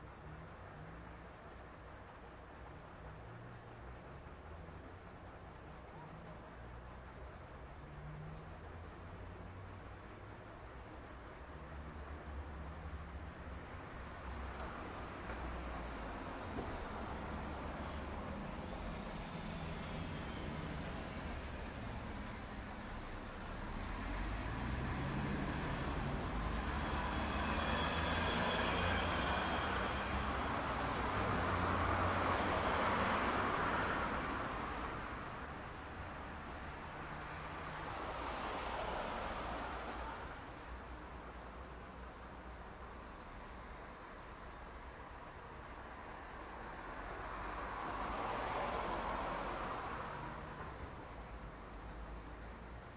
描述：在汽车站等待，右边是柴油货车
Tag: 总线 道路 交通